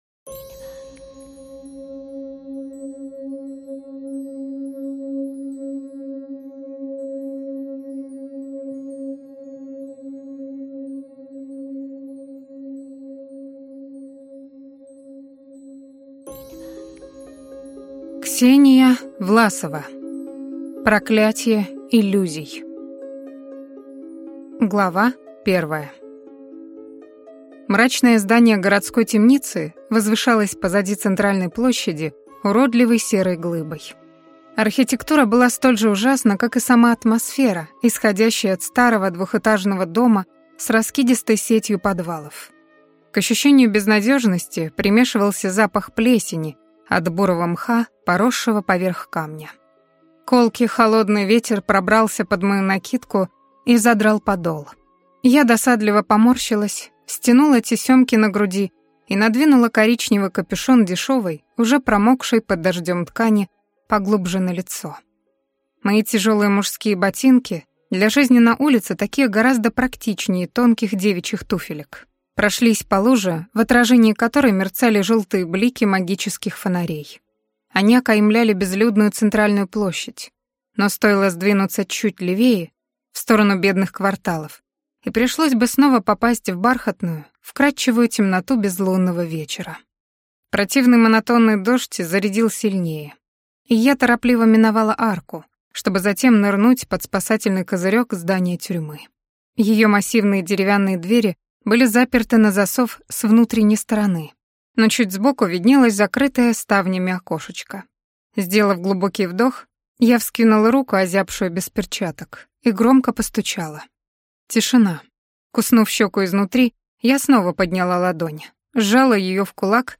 Аудиокнига Проклятье иллюзий | Библиотека аудиокниг